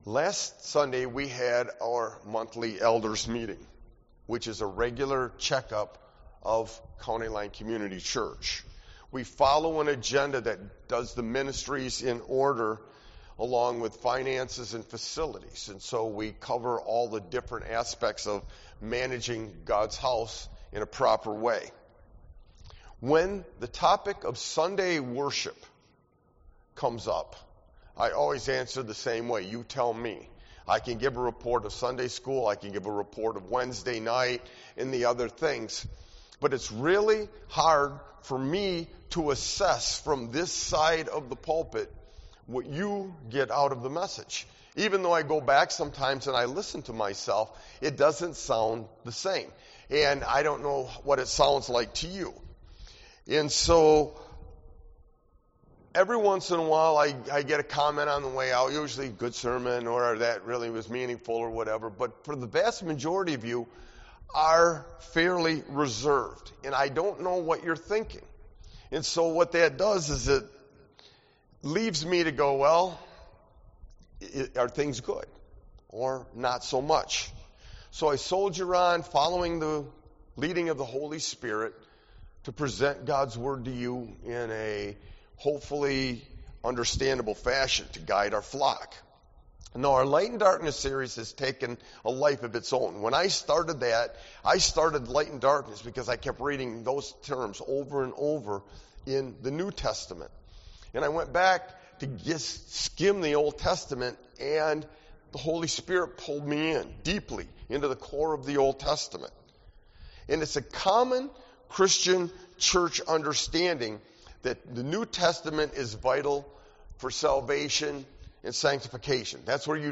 Sermon-Light-and-Darkness-LXIV-52321.mp3